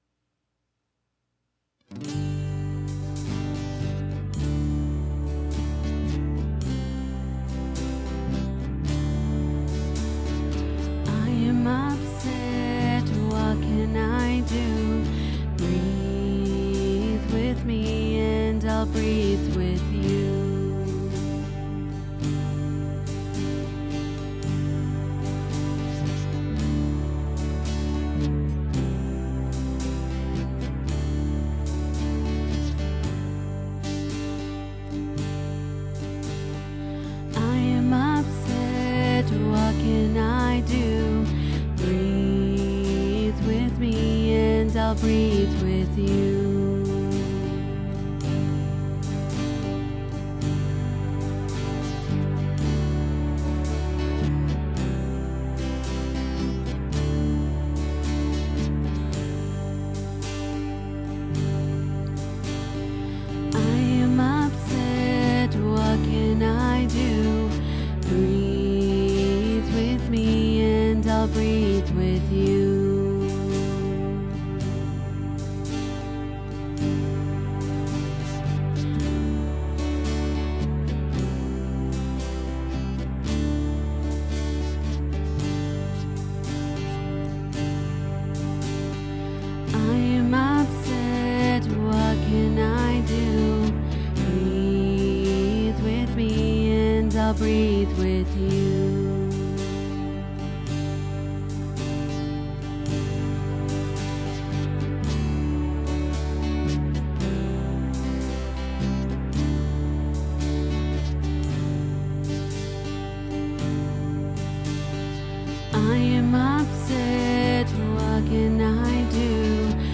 with instrumentals